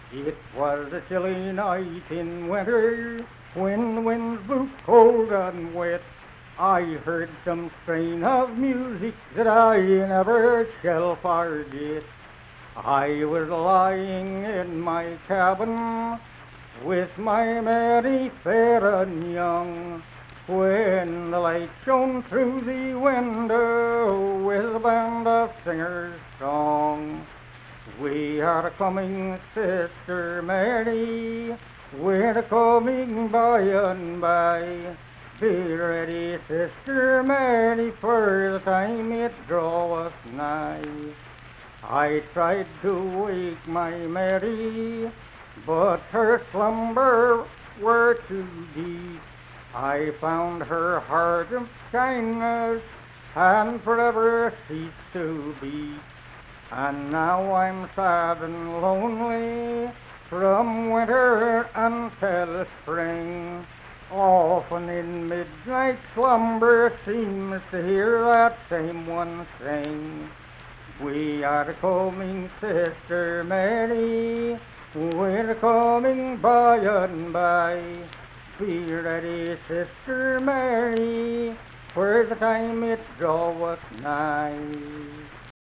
Unaccompanied vocal and fiddle music
Verse-refrain 6(4w/R).
Voice (sung)